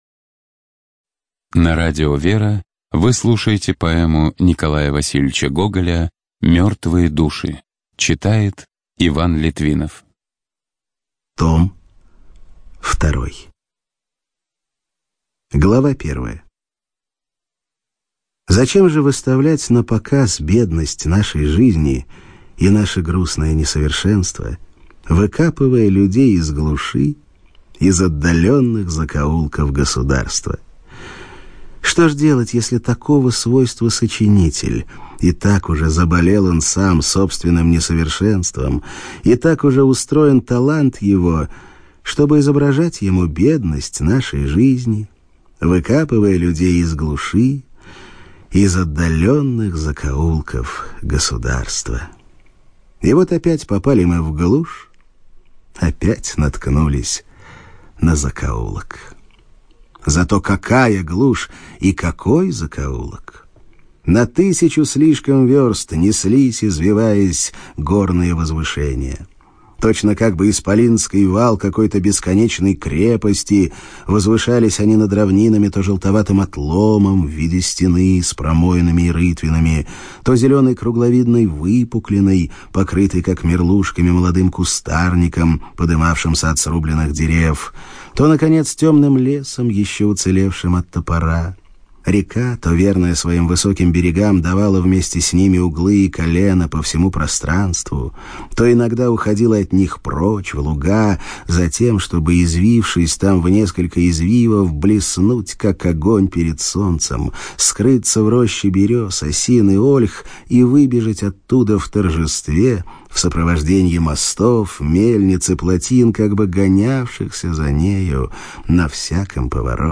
ЖанрКлассическая проза
Студия звукозаписиРадио Вера